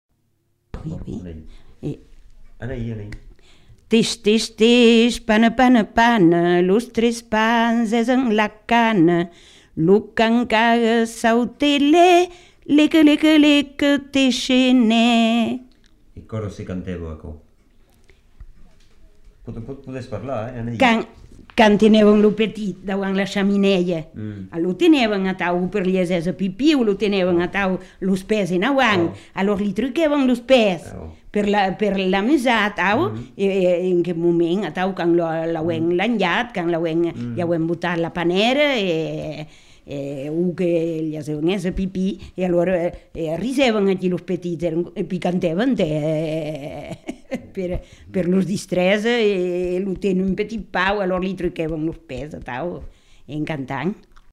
Aire culturelle : Marmandais gascon
Lieu : Tonneins
Genre : forme brève
Effectif : 1
Type de voix : voix de femme
Production du son : chanté
Classification : formulette enfantine